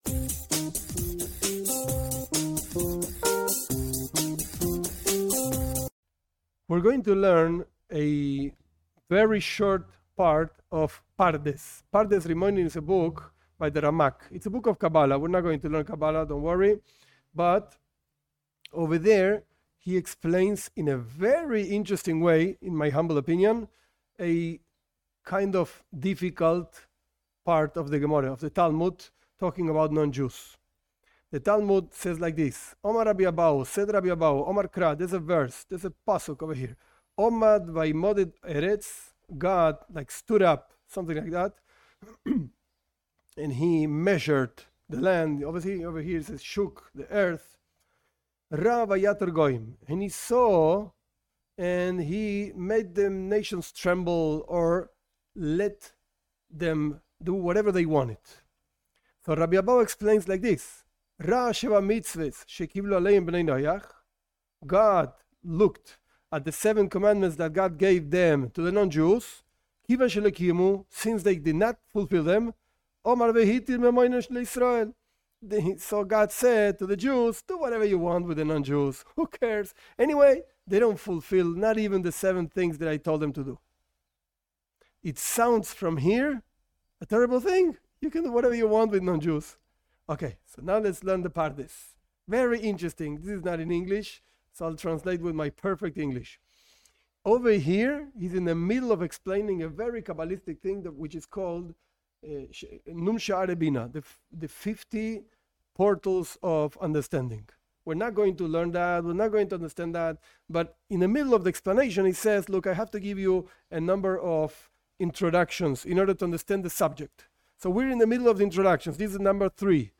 This class is a textual learning of a text by Rabi Moshe Cordovero, the Ramak, from his book Pardes Rimonim (Shaar 13, ch. 3), where he explains the exile in Egypt and the development of the souls of non Jews mixed with the souls of Jews.